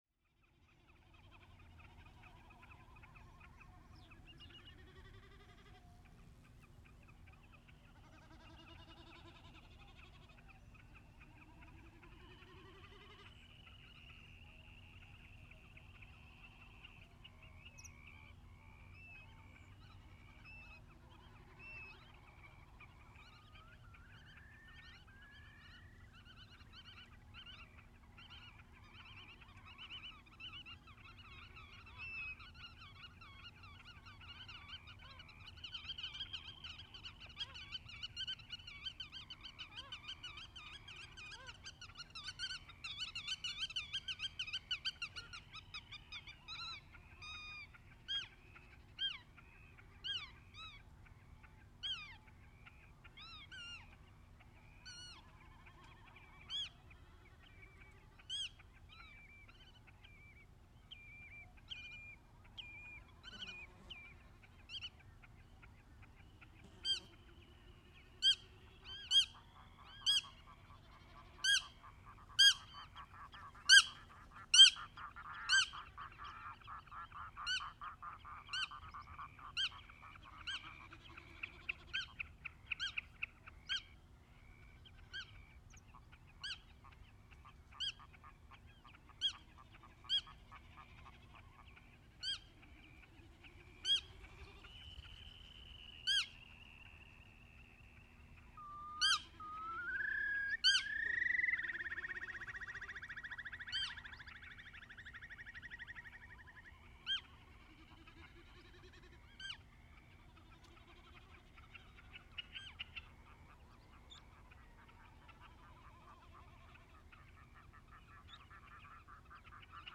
Birds also started to sing without me as a human being triggering a warning sound all over the fields.
So I drove along the trail and suddenly I was in a green oasis that contained a lot of bird life that I did not know about. So I found a place where it was easy to find as many recording sites as possible within walking distance. In the end, 4 recording devices were running that night.